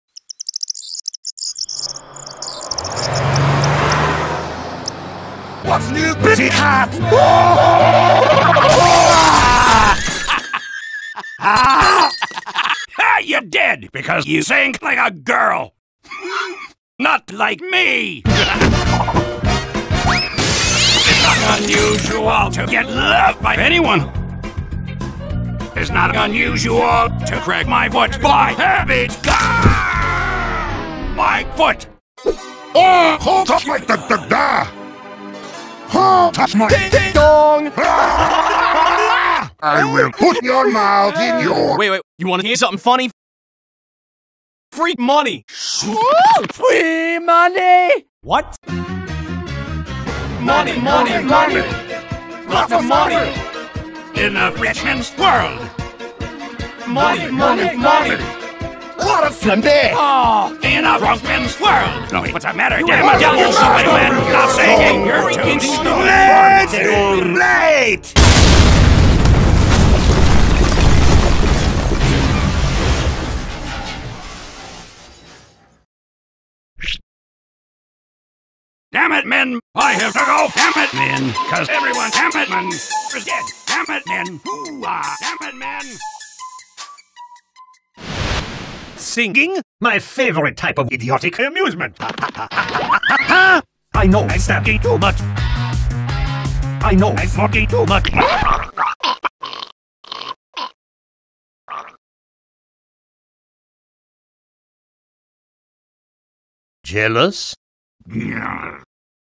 BPM90-168
Audio QualityPerfect (High Quality)